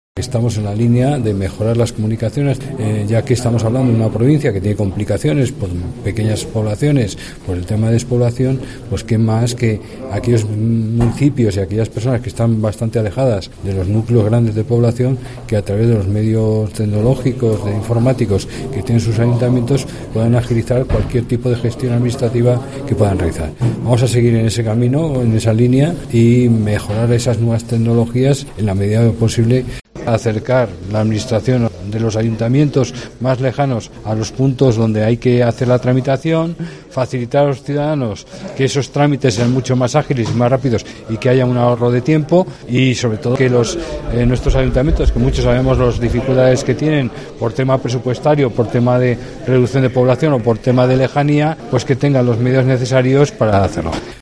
El Presidente de la Diputación señala algunas de las ventajas que ofrecerán estos equipos informáticos